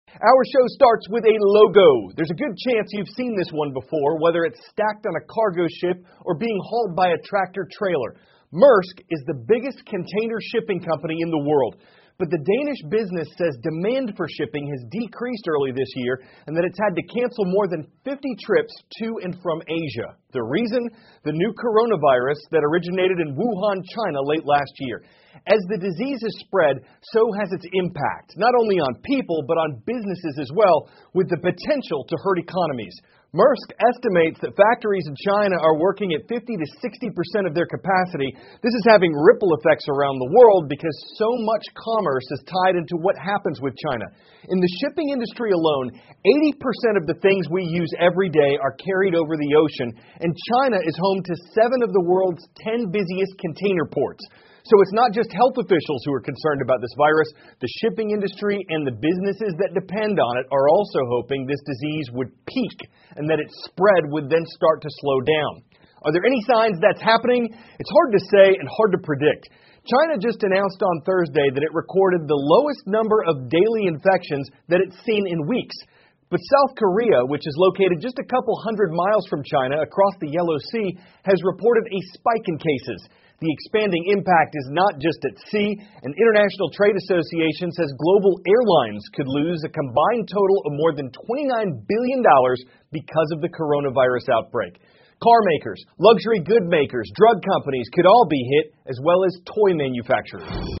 美国有线新闻 CNN 新冠病毒疫情影响全球航运业 听力文件下载—在线英语听力室